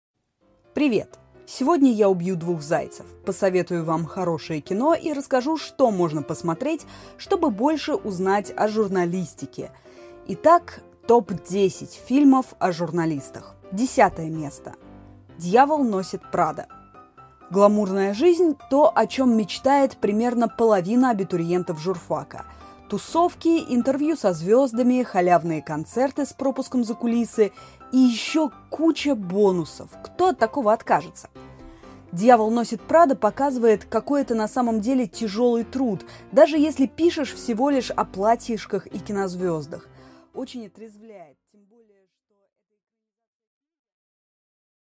Аудиокнига 10 фильмов о журналистике | Библиотека аудиокниг